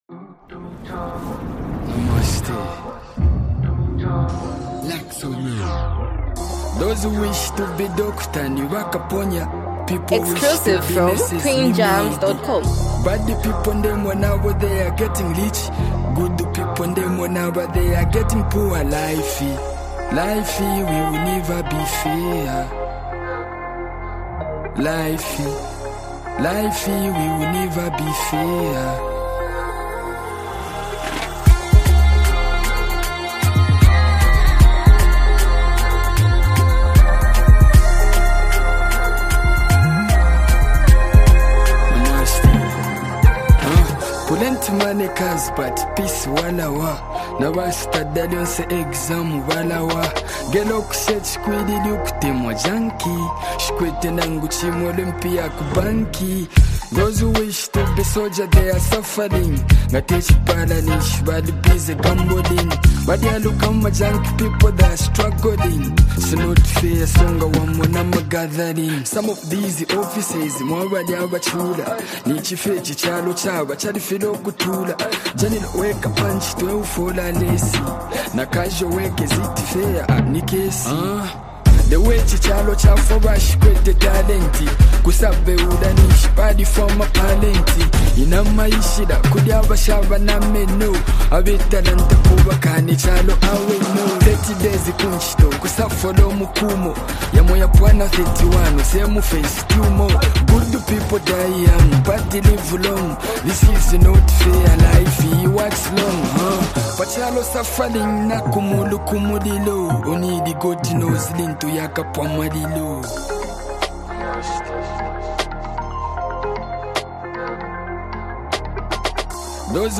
a deeply reflective and emotional song
a soulful and touching hook
a motivational yet realistic anthem